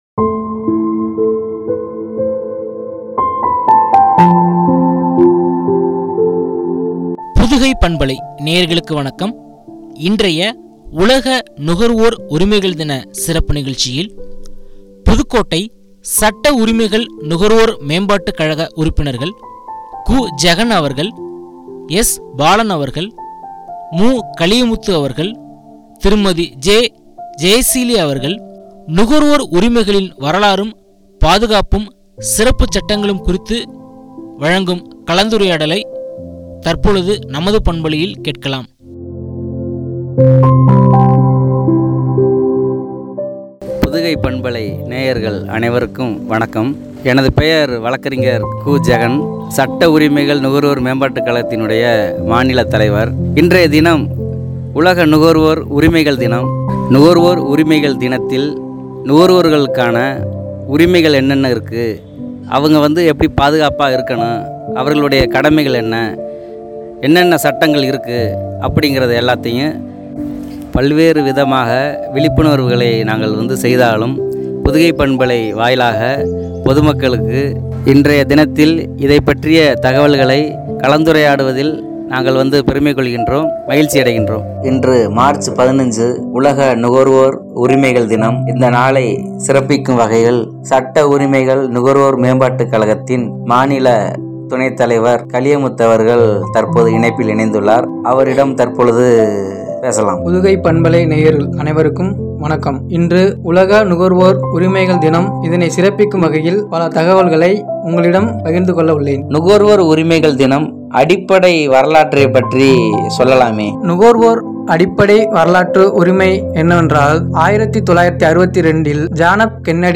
சிறப்புகள்” என்ற தலைப்பில் வழங்கிய உரையாடல்.